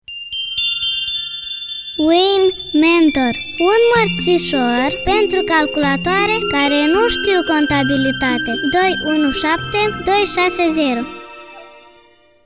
SPOTURI RADIO